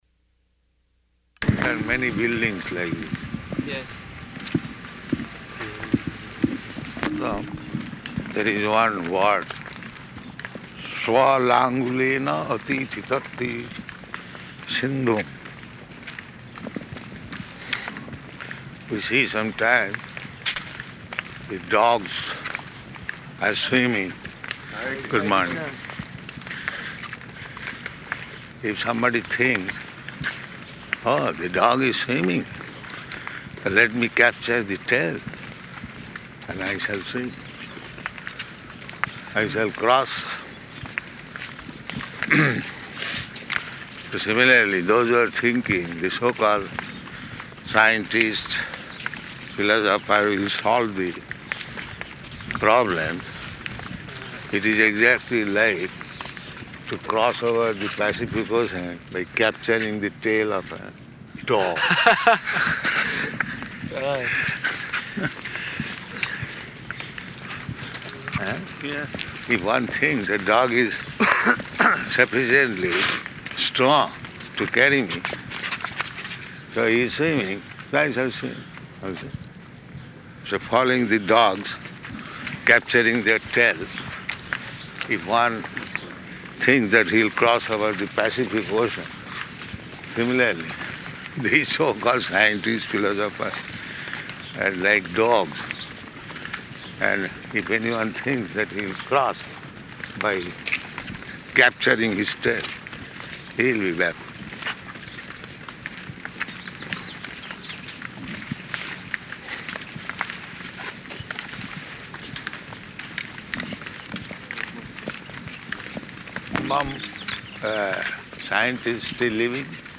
Morning Walk --:-- --:-- Type: Walk Dated: July 18th 1975 Location: San Francisco Audio file: 750718MW.SF.mp3 Prabhupāda: ...there are many buildings like this.